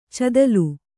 ♪ cadalu